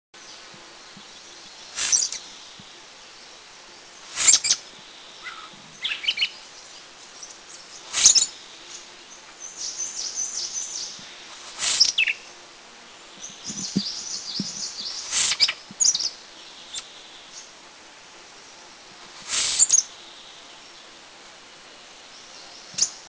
Brachypteryx montana
White-browed Shortwing
White-browedShortwingTwitter.mp3